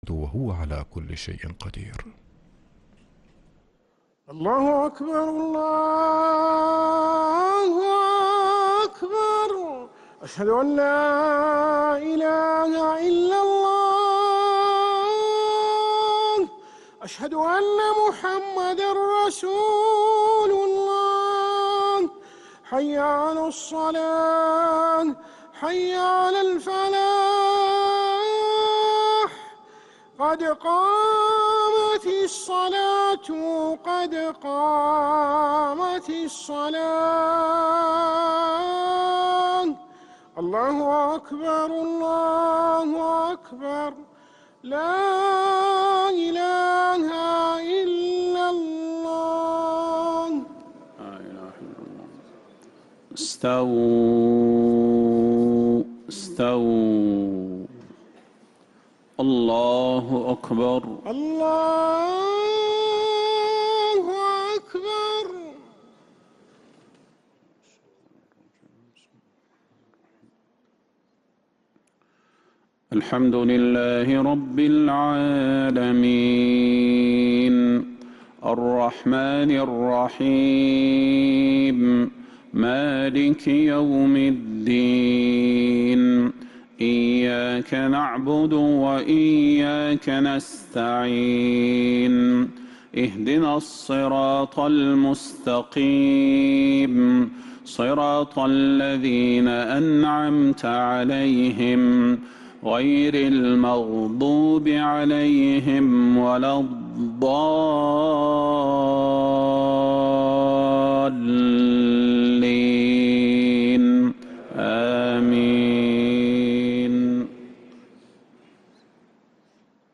Haramain Salaah Recordings: Madeenah Maghrib - 24th April 2026